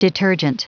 Prononciation du mot detergent en anglais (fichier audio)
Prononciation du mot : detergent